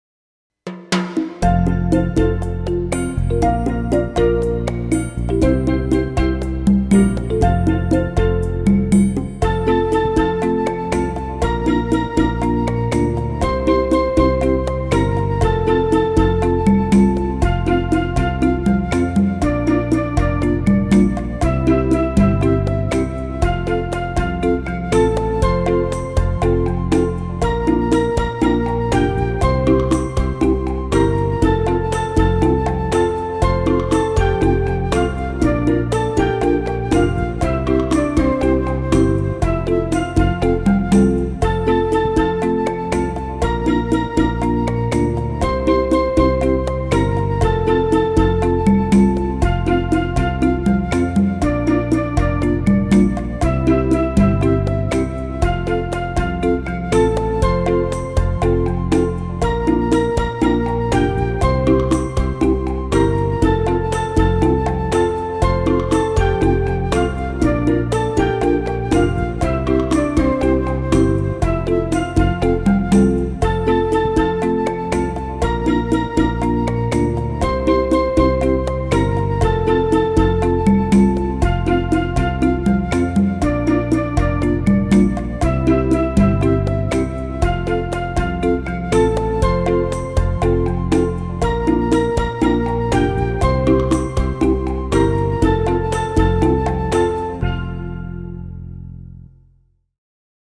Base_musical_Janie_Mama_calypso.mp3